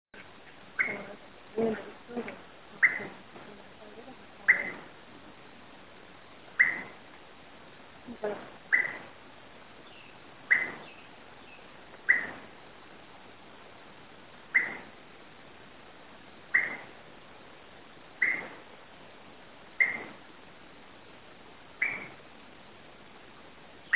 Short-tailed Antthrush (Chamaeza campanisona)
Un ejemplar vocalizando con un contacto
Location or protected area: Parque Provincial Cruce Caballero
Condition: Wild
Certainty: Recorded vocal